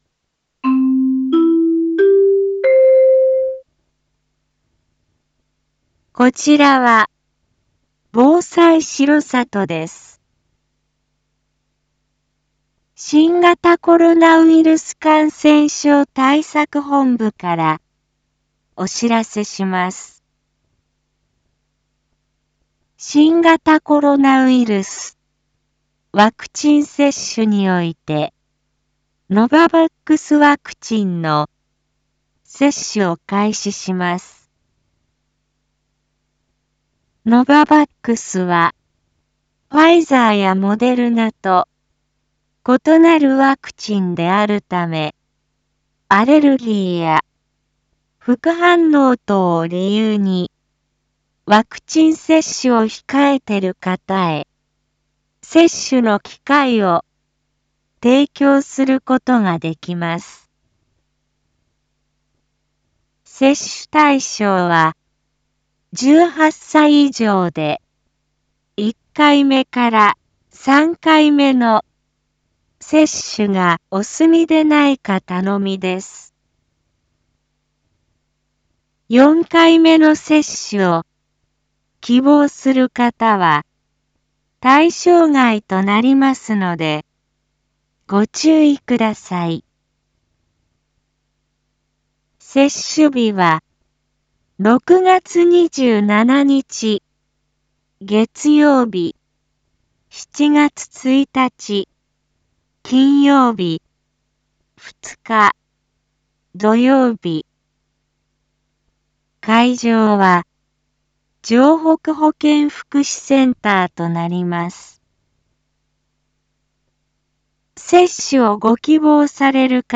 一般放送情報
Back Home 一般放送情報 音声放送 再生 一般放送情報 登録日時：2022-06-16 19:02:51 タイトル：新型コロナウイルスワクチン接種（ノババックス） インフォメーション：こちらは、防災しろさとです。